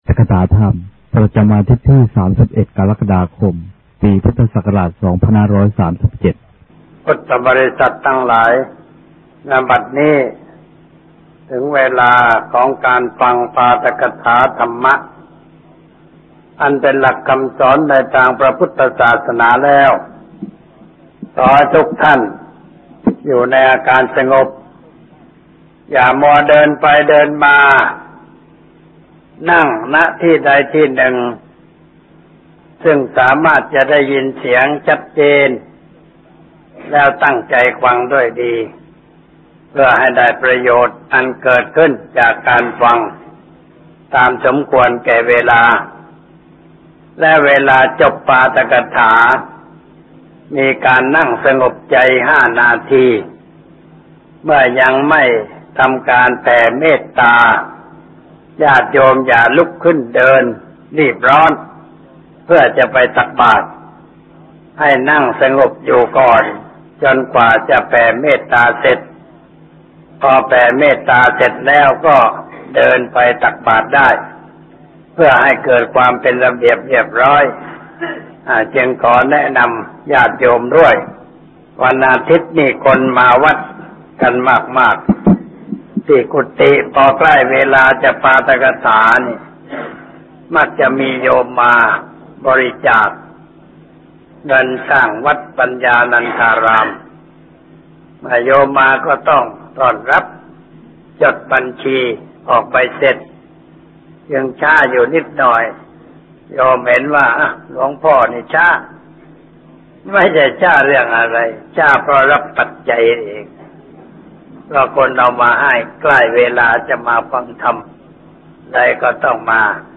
ปาฐกถาธรรม (00.01 เสียงไม่ชัดเจน) ที่ ๓๑ กรกฎาคม ปีพุทธศักราช ๒๕๓๗ พุทธบริษัททั้งหลาย ณ บัดนี้ ถึงเวลาของการฟังปาฐกถาธรรมะอันเป็นหลักคำสอนในทางพระพุทธศาสนาแล้ว ...